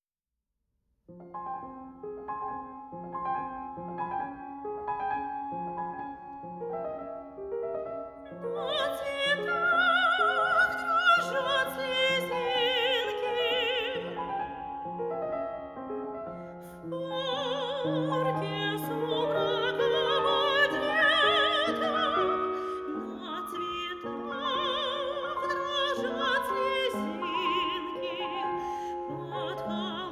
aufgenommen Herbst 2021 im Tonstudio